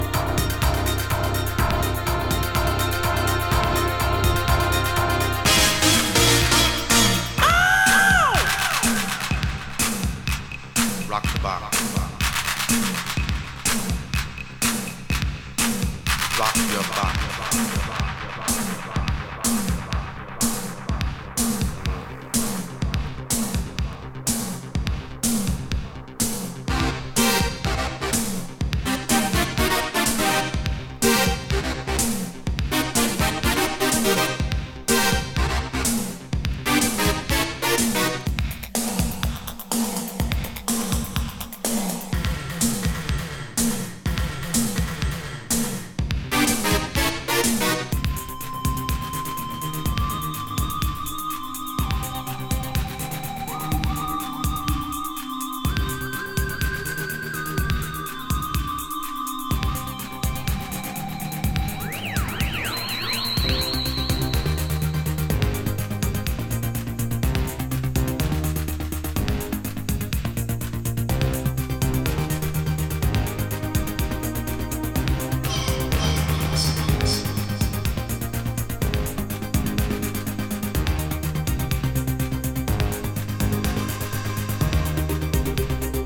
こちらは激しいドラムマシーン、高鳴るシンセがたまらないElectroチューン！